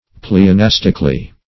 Pleonastically \Ple`o*nas"tic*al*ly\, adv.
pleonastically.mp3